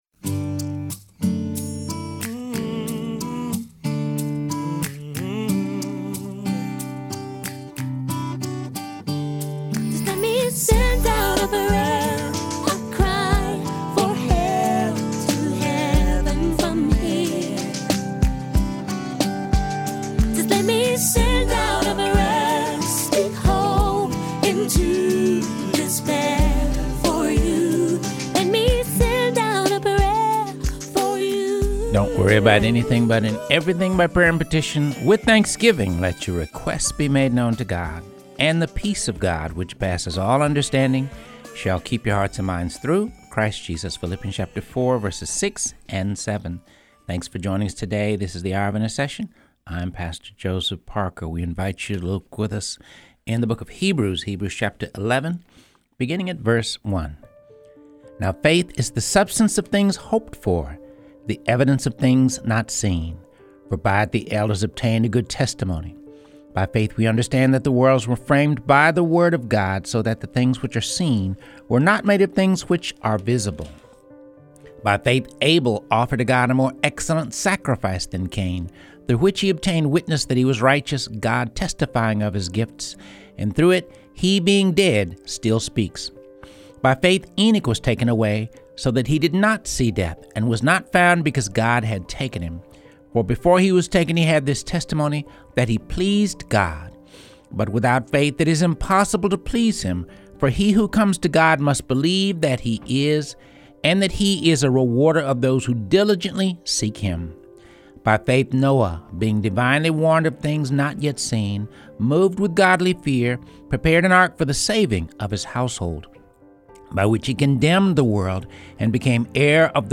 reading through the Bible